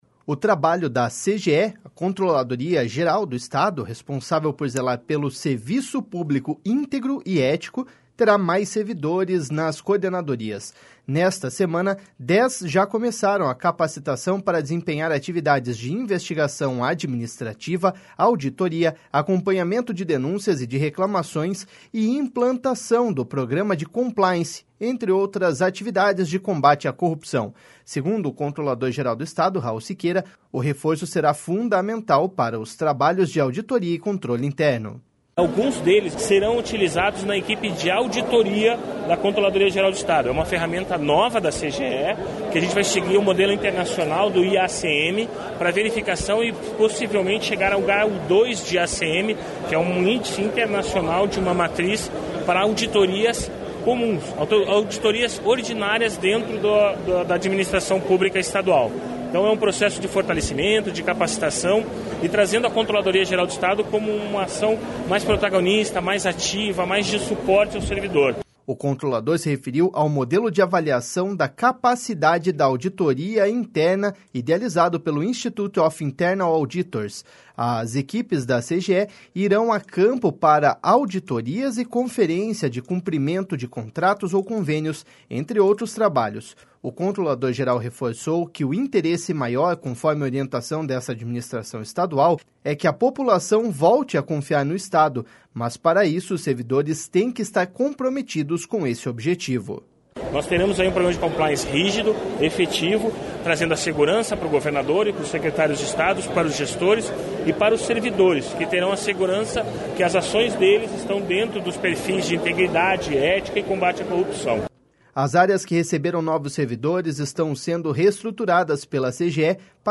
Segundo o controlador-geral do Estado, Raul Siqueira, o reforço será fundamental para os trabalhos de auditoria e controle interno.// SONORA RAUL SIQUEIRA.//